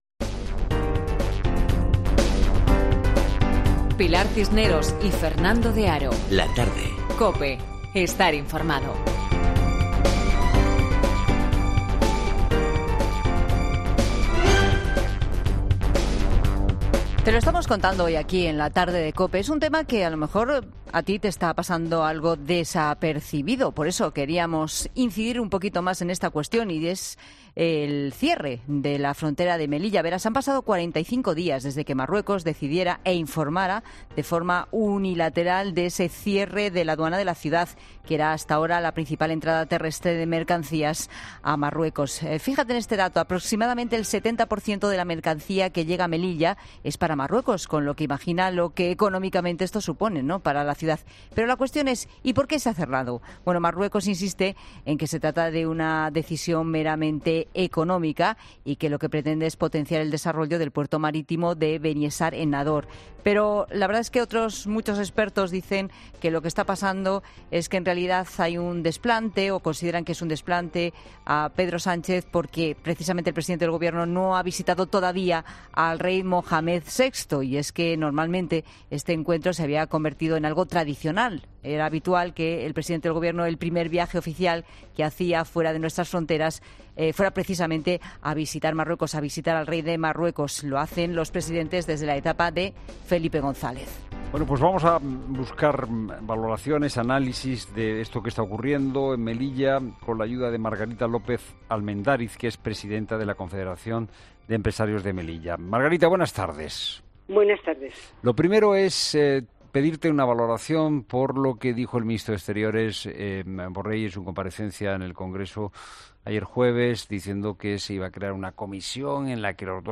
ESCUCHA LA ENTREVISTA COMPLETA EN 'LA TARDE' Pero lo cierto es que el cierre de la aduana no ha sido el único “toque” que Marruecos ha dado a nuestro gobierno.